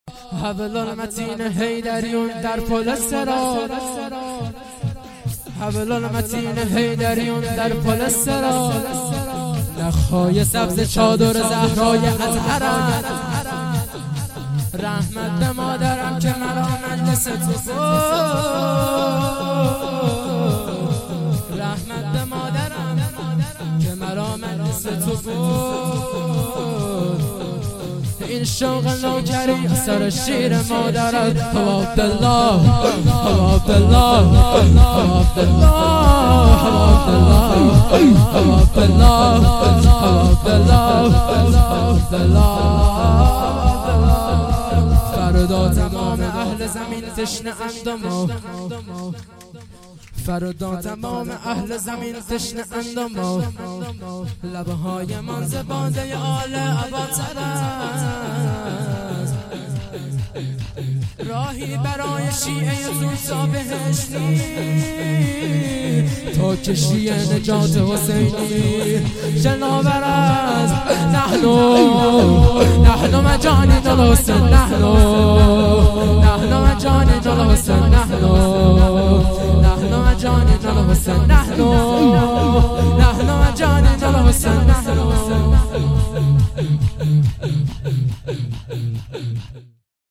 رجز و ذکر
ولادت امام علی ۱۳۹۸